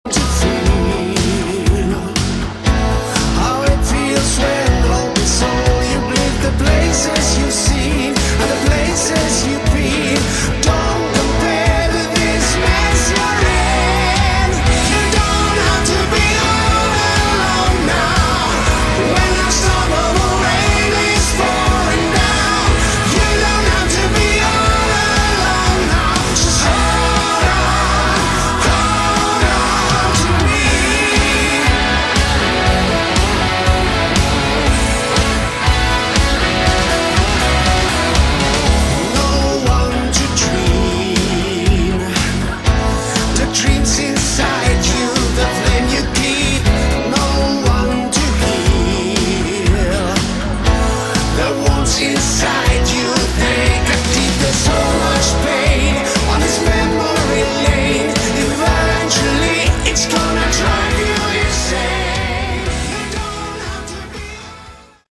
Category: Hard Rock
lead and backing vocals
guitars, keys, backing vocals
drums
bass